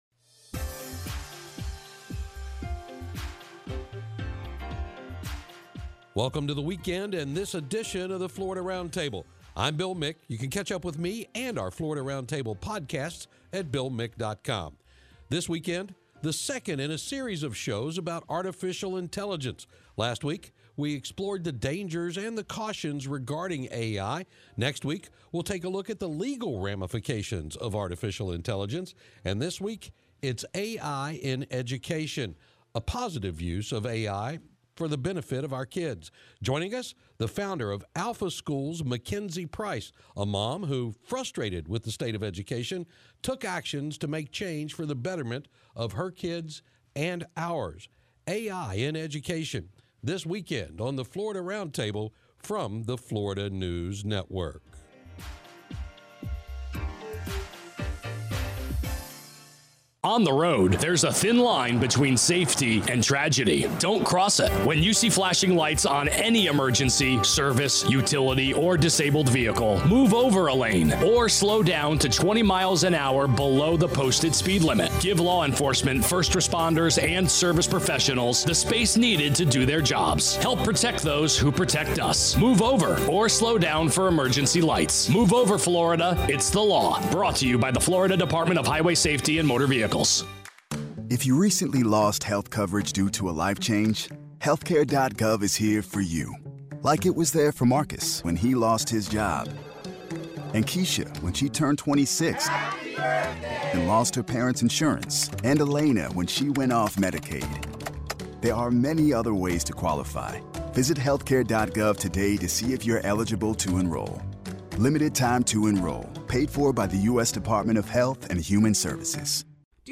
FNN's Florida Roundtable is a weekly, one-hour news and public affairs program that focuses on news and issues of Florida.
Florida Roundtable is heard on radio stations throughout Florida.